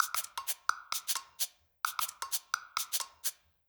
Index of /musicradar/uk-garage-samples/130bpm Lines n Loops/Beats
GA_PercE130-04.wav